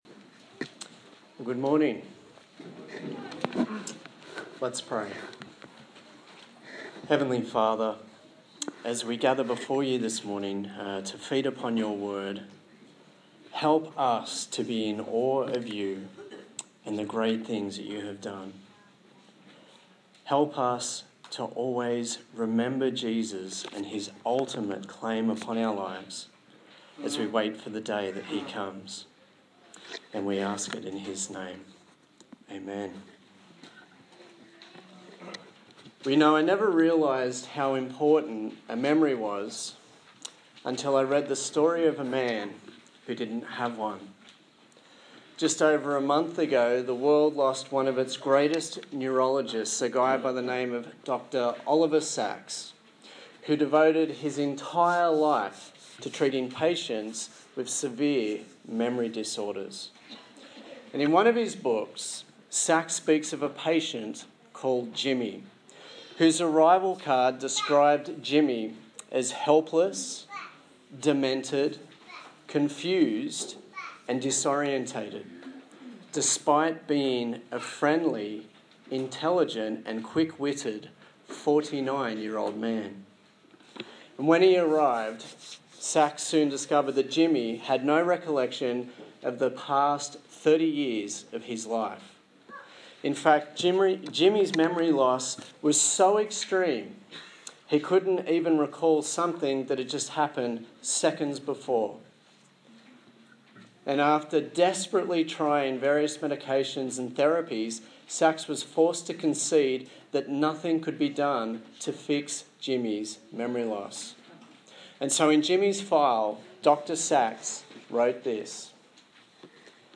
1 Samuel Passage: 1 Samuel 12 Service Type: Sunday Morning